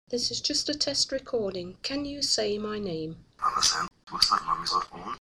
Here is a test recording from the beginning of today’s session.